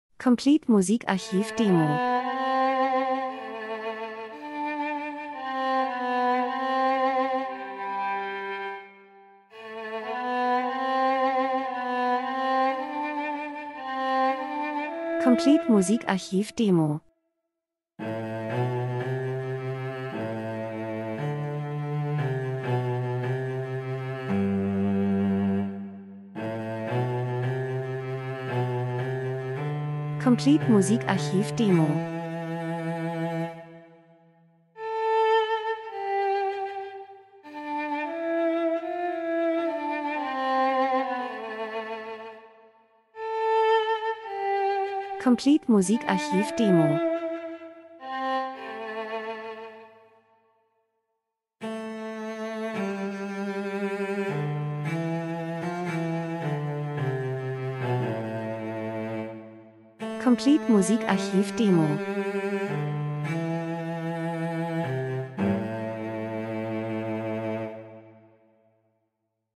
Gemafreie Klassik Orchesterbearbeitung 40 summer9
optimistisch nach vorn